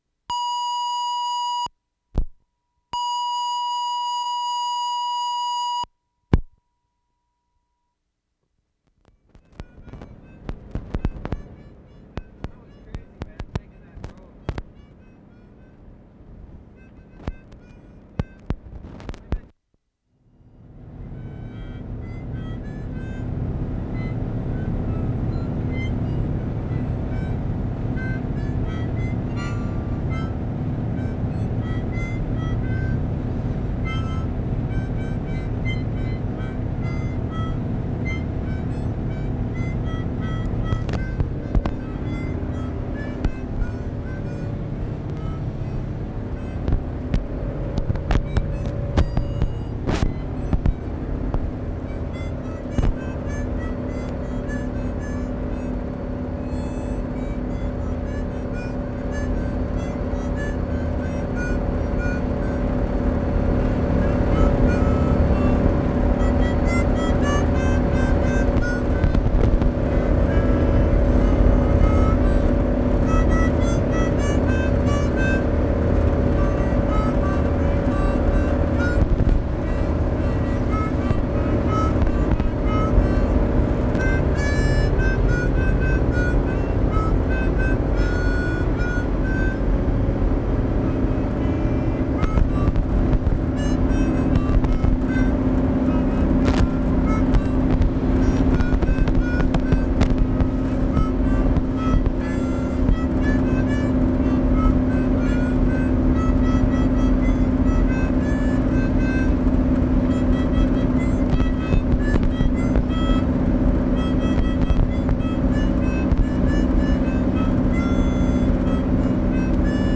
mouth organ